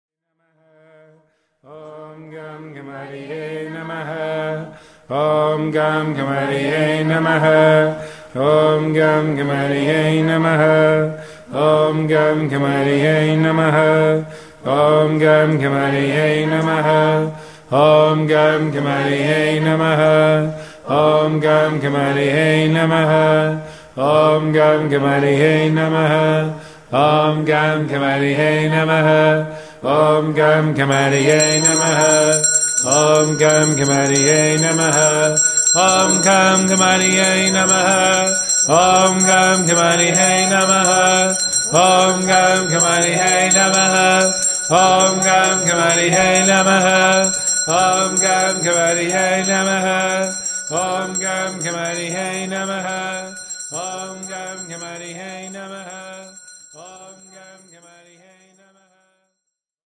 Live Kirtan Chanting CD